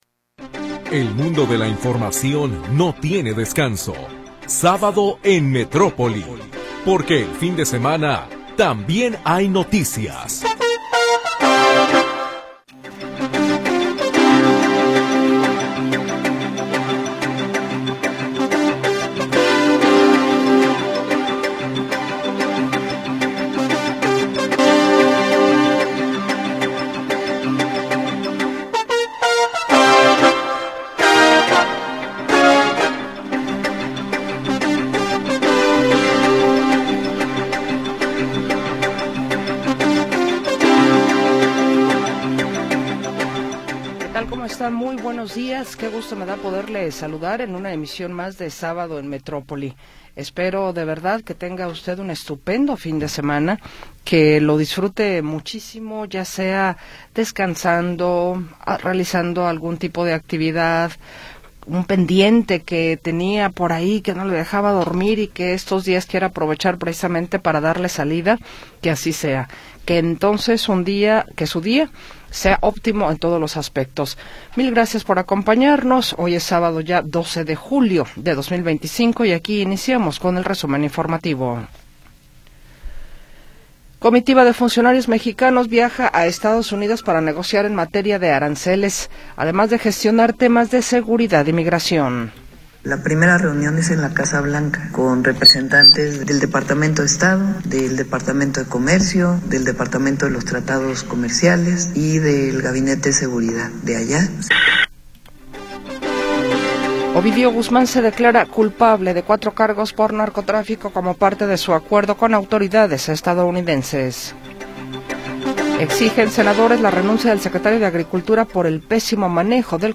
Primera hora del programa transmitido el 12 de Julio de 2025.